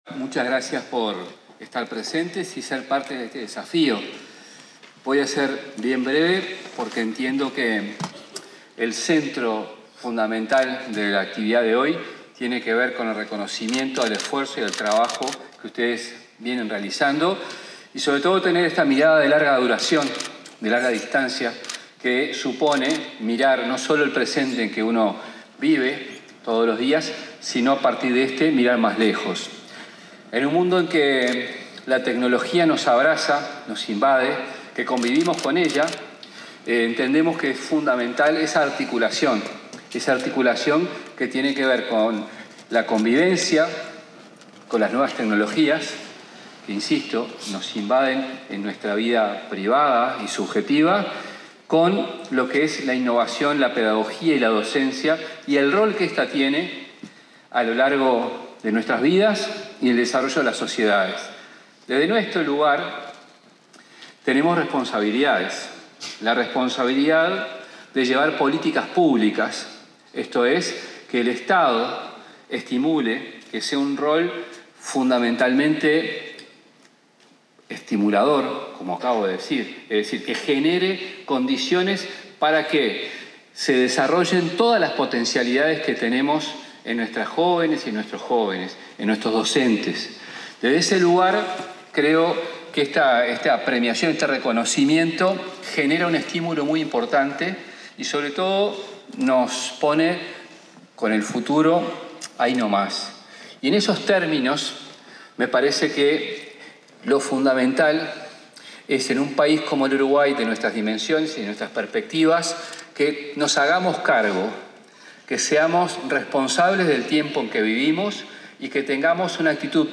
discursos.mp3